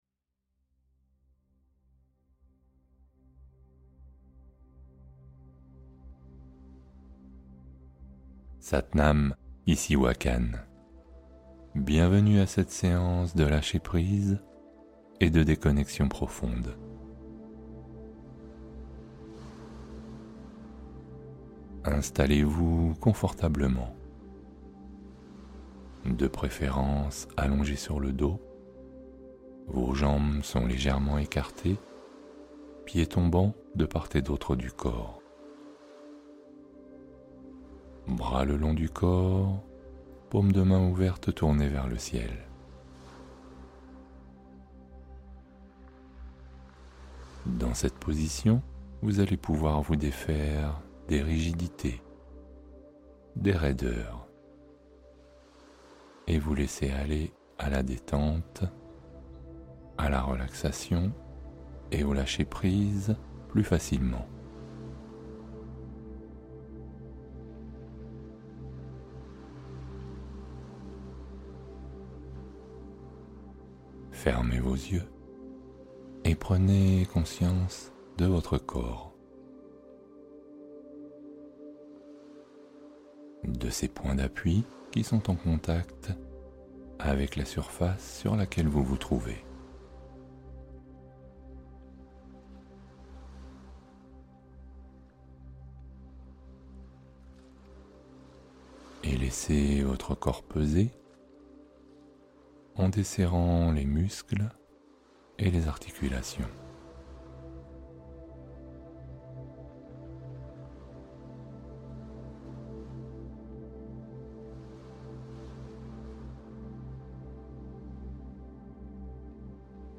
Déconnexion : Méditation guidée pour lâcher prise et retrouver la paix